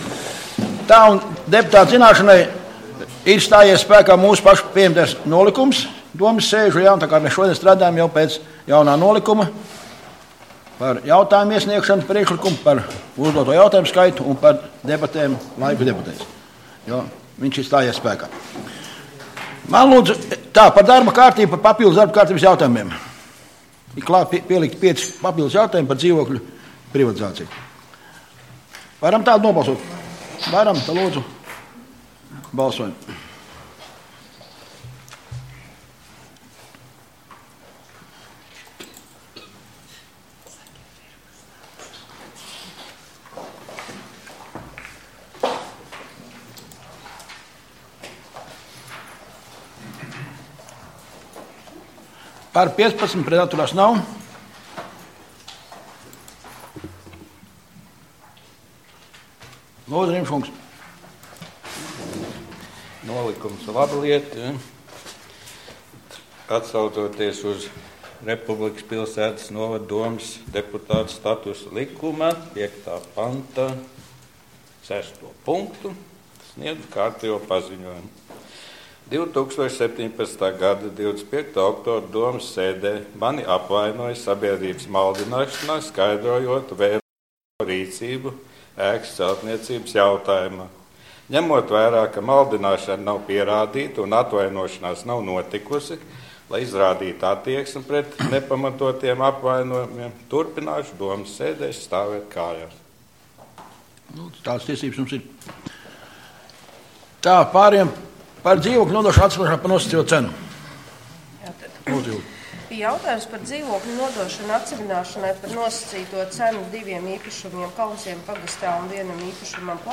Domes sēde Nr. 2